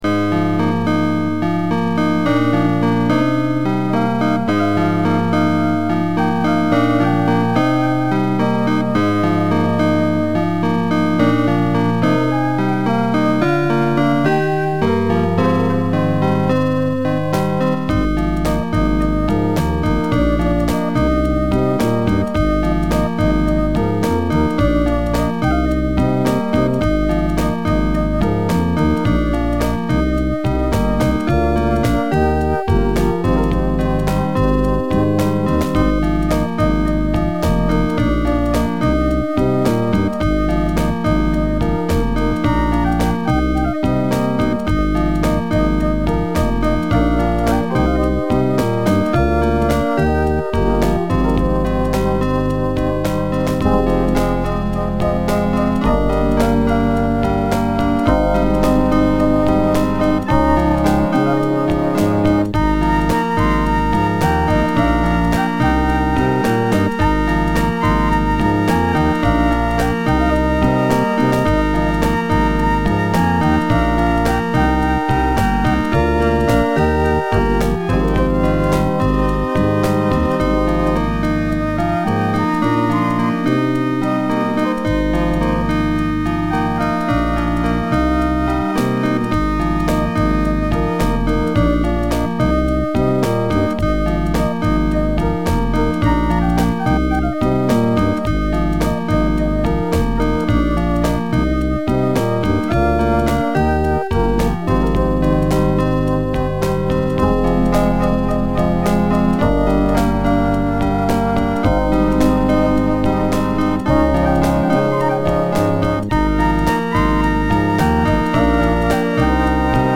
Avance Logic FM MIDI Engine (from an ALS4000 PCI card)